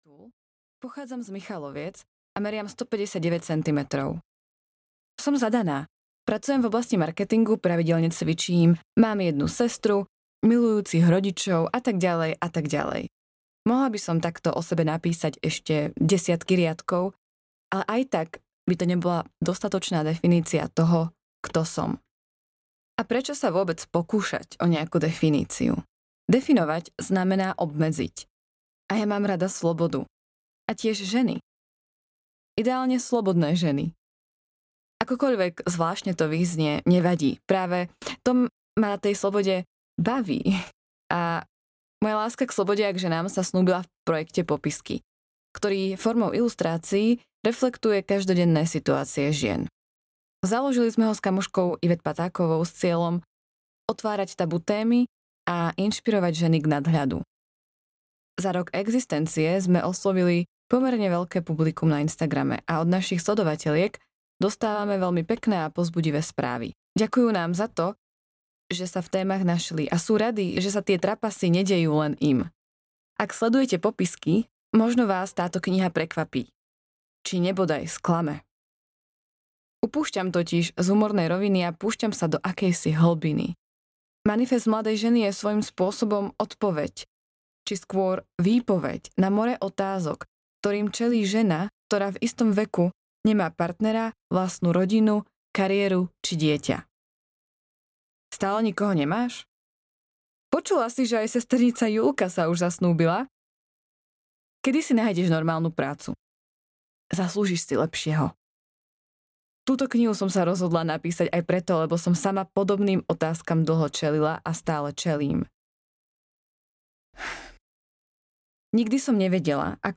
Manifest mladej ženy audiokniha
Ukázka z knihy